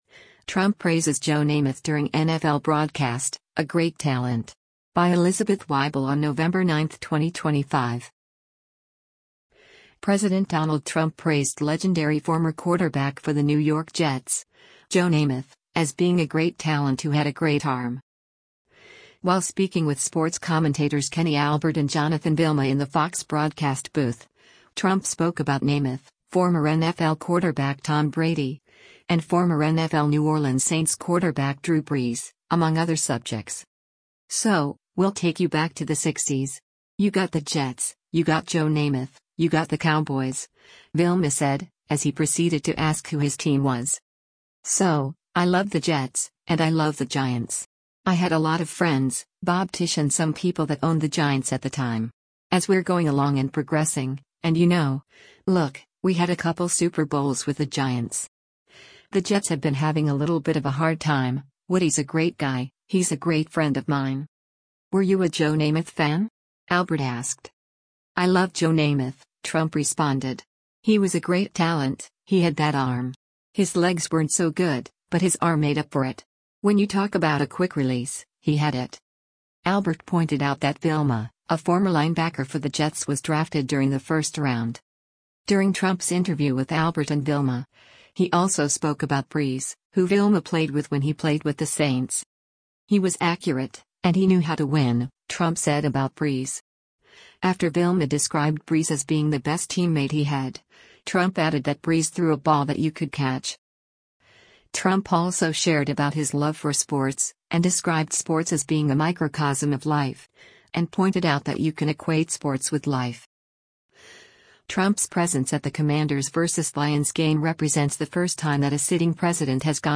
Trump Praises Joe Namath During NFL Broadcast: ‘A Great Talent’
While speaking with sports commentators Kenny Albert and Jonathan Vilma in the FOX broadcast booth, Trump spoke about Namath, former NFL quarterback Tom Brady, and former NFL New Orleans Saints quarterback Drew Brees, among other subjects.